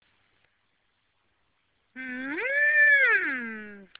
Downloadable sound effect